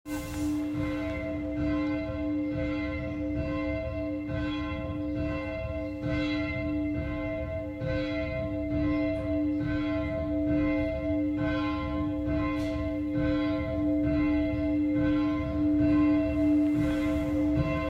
glocken-la-uten-kapelle-uhwiesen.m4a